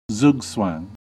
Uttal
IPA : /ˈzʌɡzwæŋ/